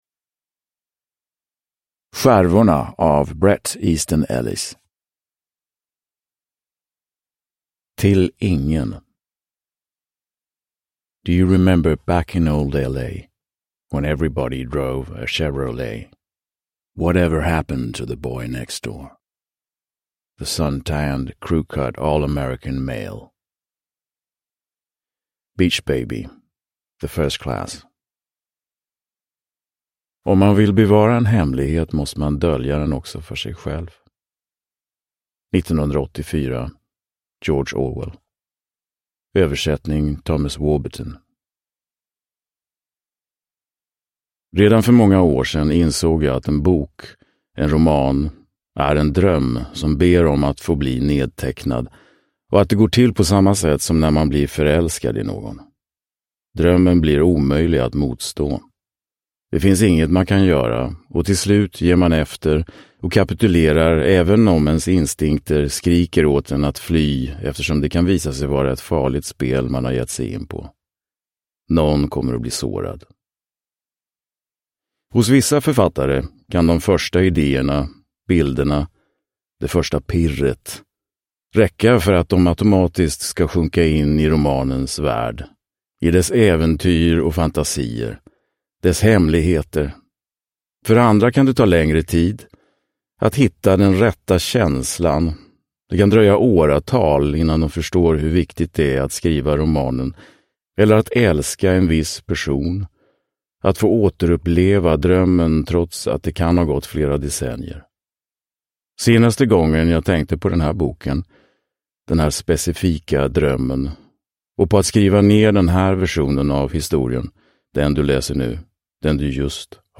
Skärvorna – Ljudbok – Laddas ner